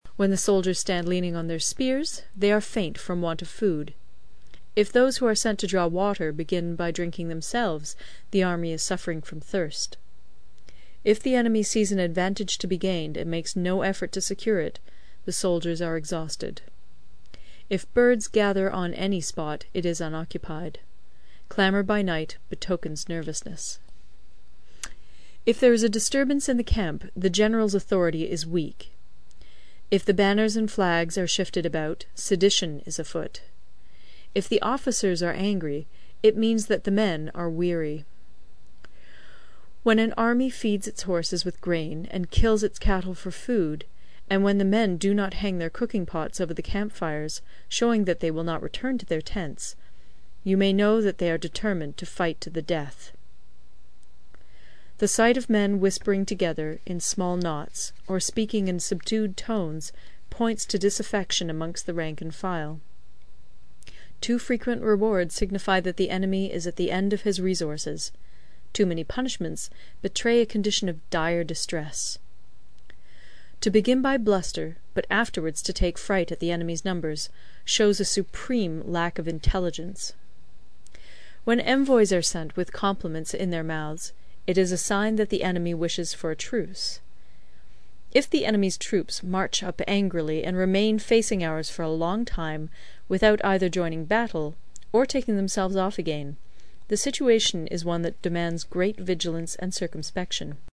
有声读物《孙子兵法》第55期:第九章 行军(6) 听力文件下载—在线英语听力室